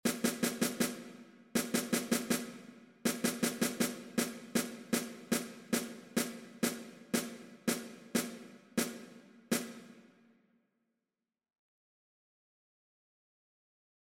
Ao primeiro corte engadíuselle un ritardando dende a metade ata o final. O grupo enteiro debe interpretar as corcheas ralentizando gradualmente o tempo ata rematar na negra final.
Logo do corte volve o ritmo base ao tempo inicial.